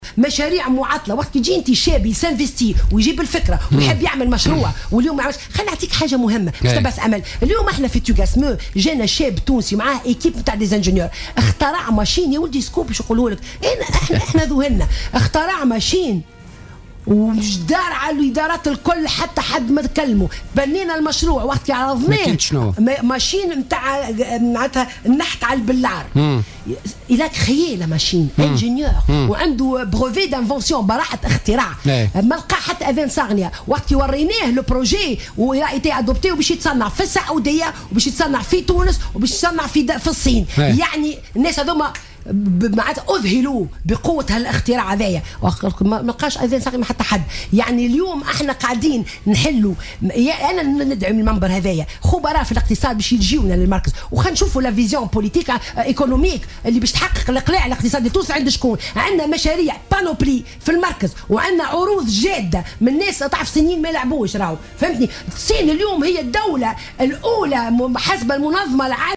وأضافت في "الجوهرة أف أم" ببرنامج "بوليتيكا" أن المركز التونسي الصيني تبنى هذا الاختراع الباهر الذي لقي اهتماما من السعودية والصين والقطاع الخاص في تونس لاحقا.